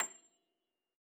53j-pno29-A6.aif